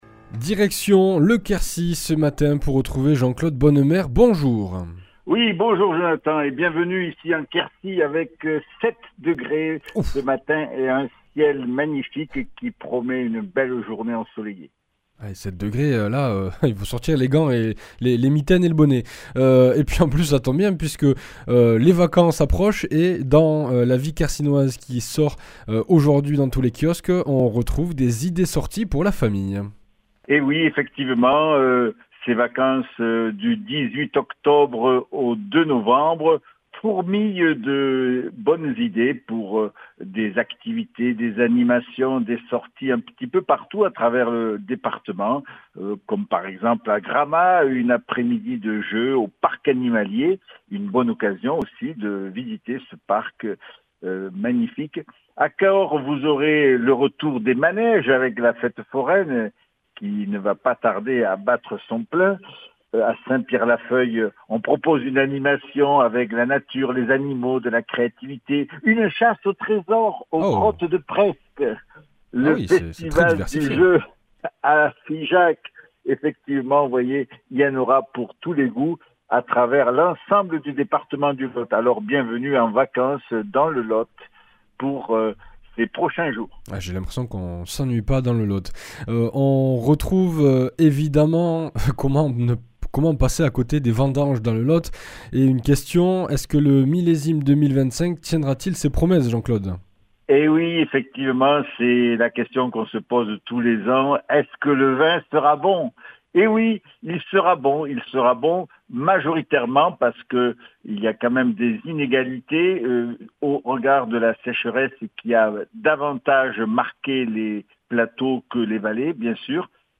Blues
Une émission présentée par